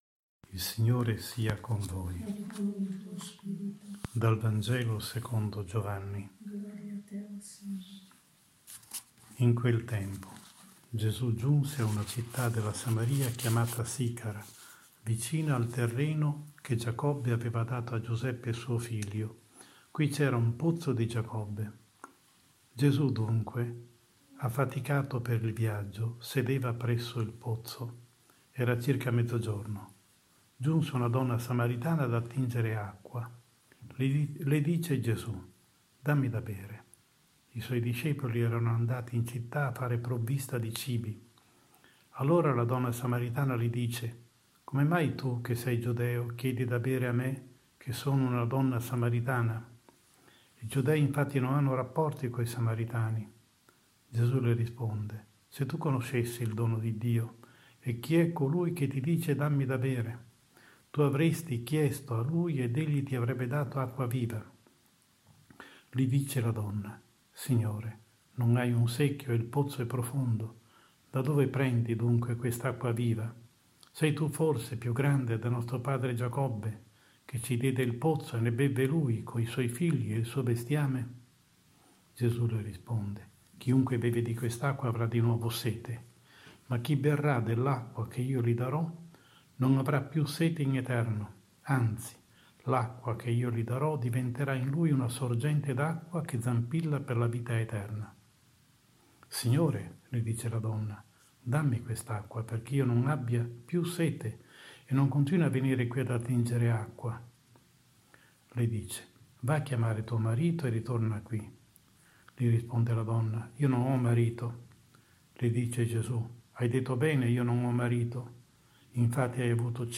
15 Marzo 2020 III DOMENICA DI QUARESIMA (ANNO A) – omelia